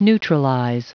Prononciation du mot neutralize en anglais (fichier audio)
neutralize.wav